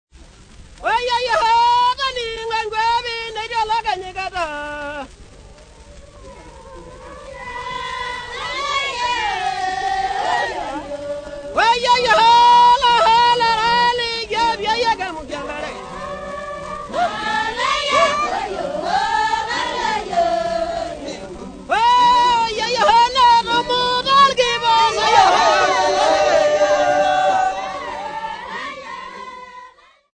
Arusha men and women
Popular music--Africa
Field recordings
sound recording-musical
Osingolio Bunya dance song for men and women